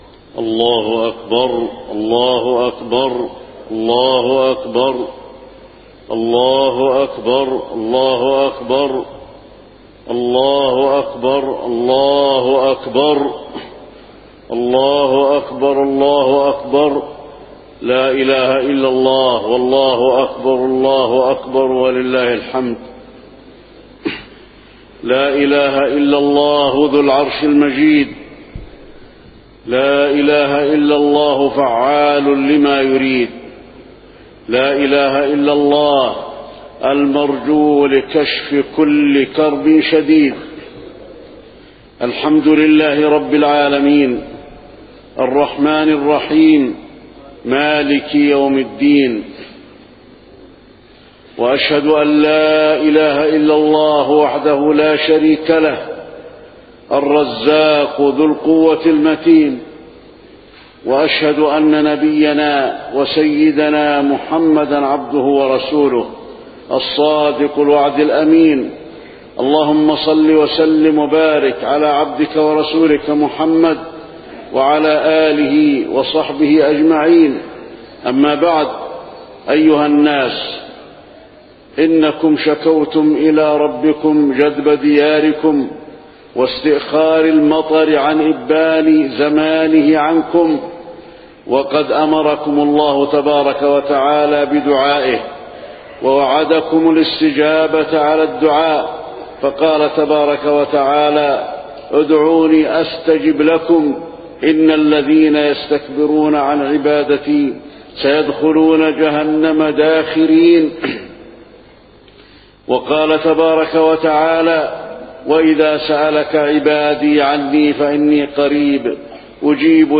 خطبة الاستسقاء - المدينة- الشيخ صلاح البدير
تاريخ النشر ٢١ جمادى الأولى ١٤٣٢ هـ المكان: المسجد النبوي الشيخ: فضيلة الشيخ د. صلاح بن محمد البدير فضيلة الشيخ د. صلاح بن محمد البدير خطبة الاستسقاء - المدينة- الشيخ صلاح البدير